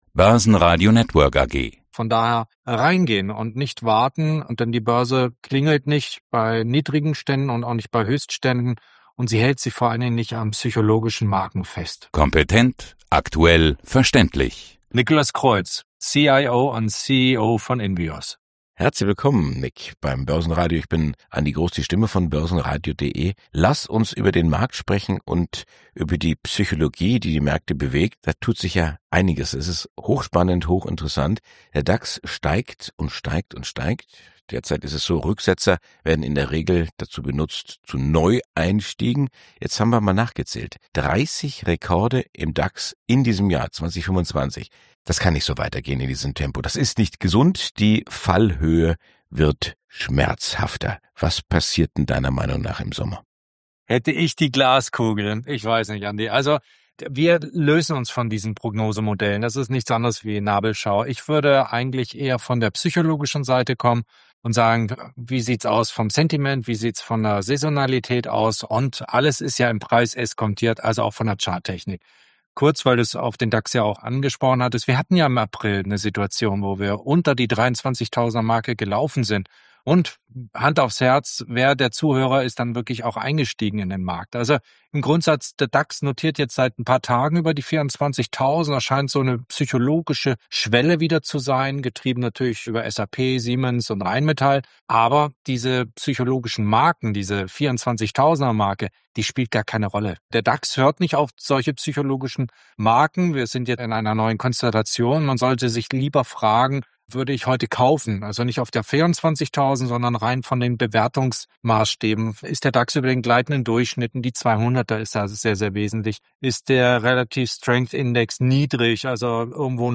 Zum Börsenradio-Interview